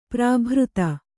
♪ prābhřta